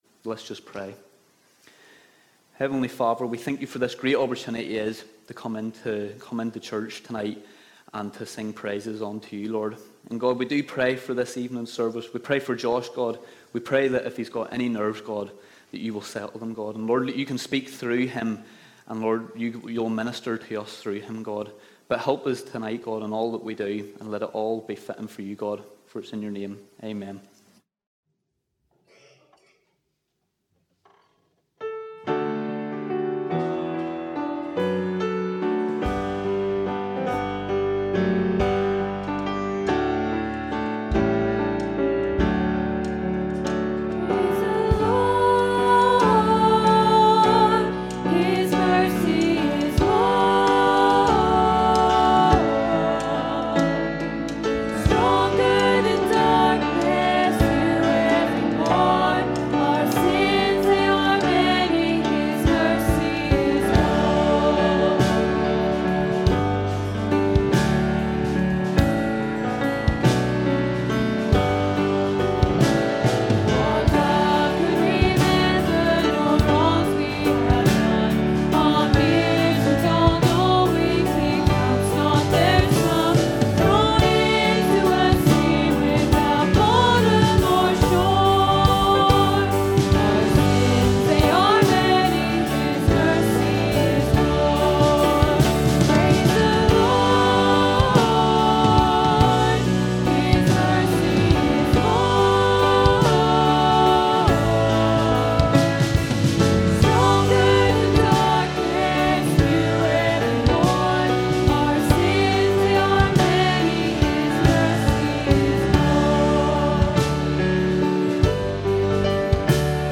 Recent sermons preached at Cullybackey Elim Church